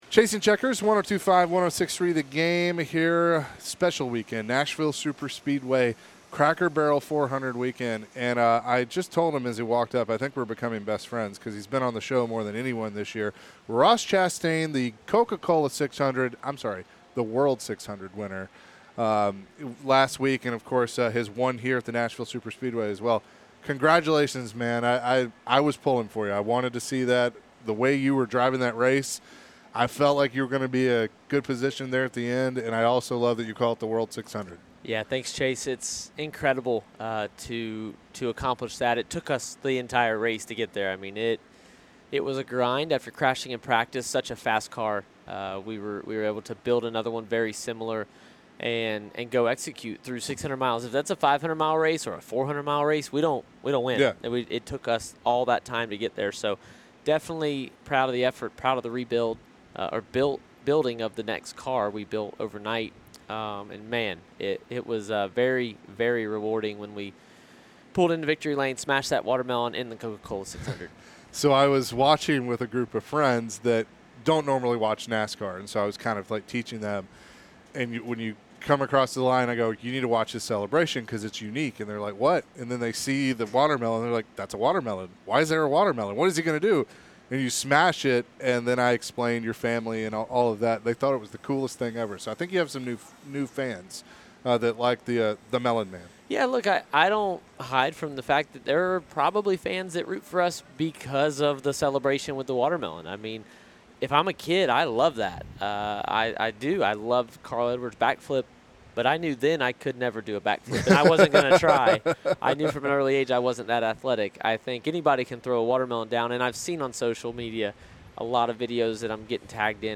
Ross Chastain at Nashville Superspeedway